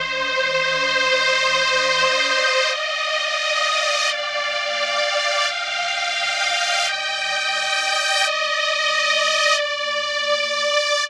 Vibes Strings 01.wav